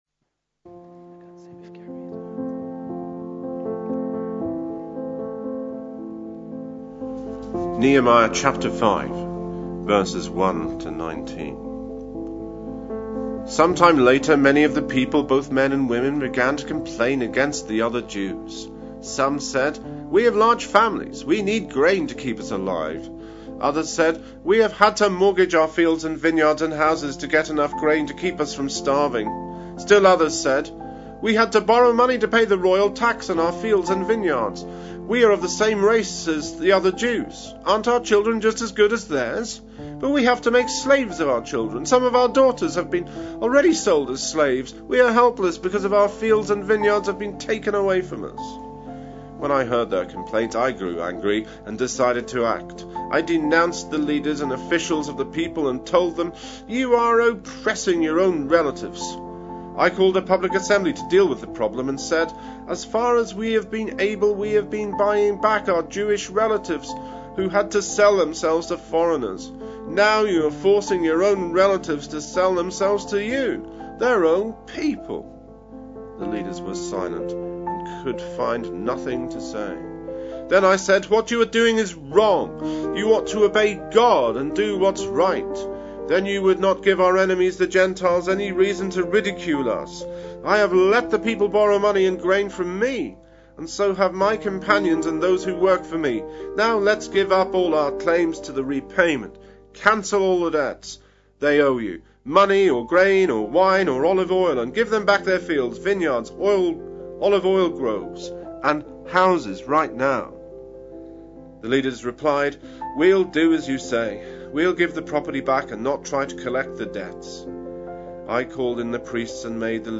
A sermon preached on 20th July, 2014, as part of our Another Brick In The Wall. series.